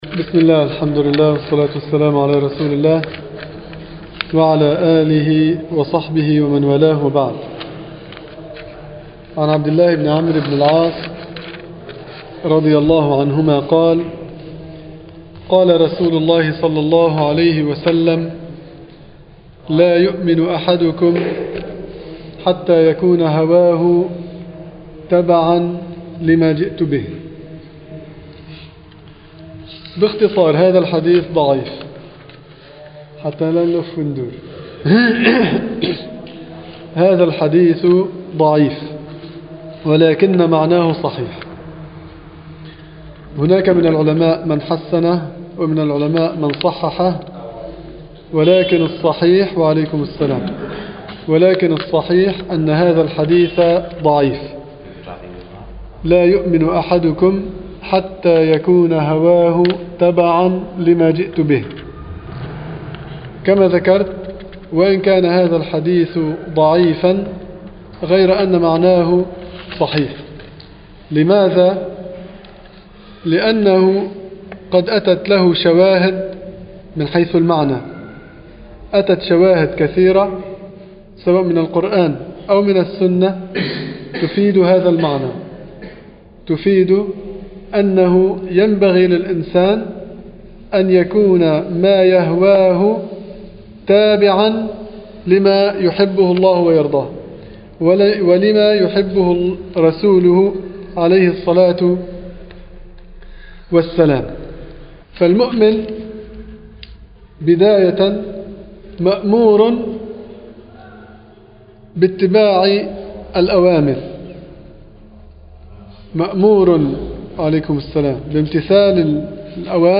في مسجد القلمون الغربي